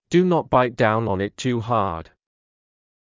ﾄﾞｩ ﾉｯﾄ ﾊﾞｲﾄ ﾀﾞｳﾝ ｵﾝ ｲｯﾄ ﾄｩｰ ﾊｰﾄﾞ